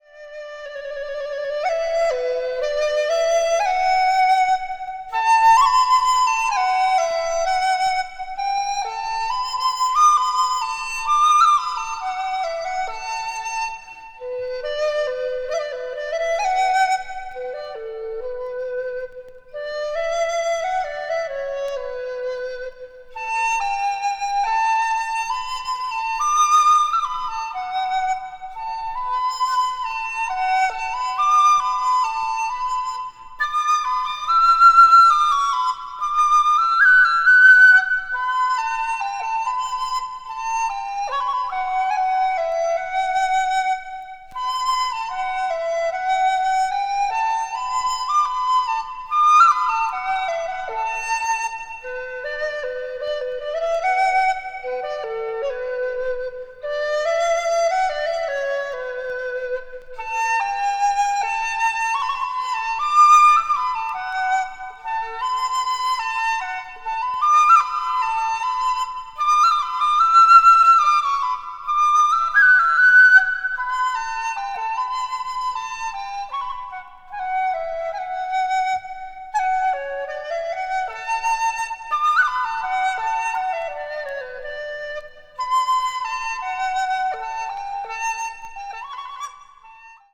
media : EX+/EX+(わずかにチリノイズが入る箇所あり)
contemporary jazz   deep jazz   ethnic jazz   spritual jazz